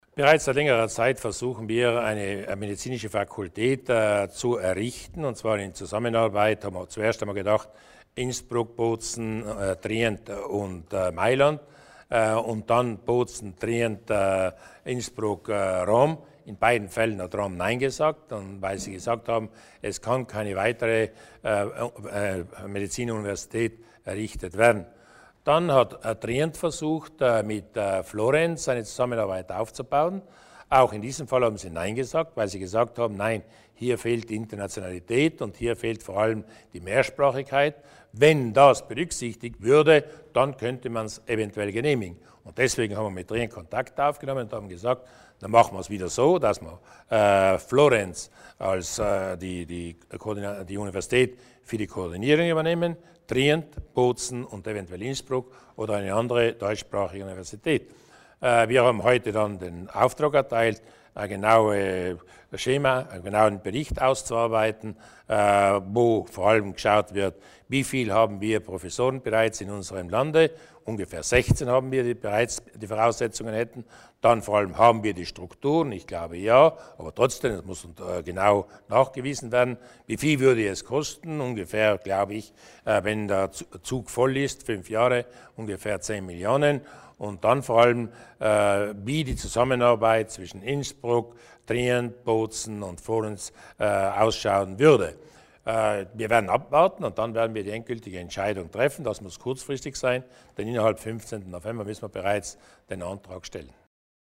Landeshauptmann Durnwalder zum Projekt Medical School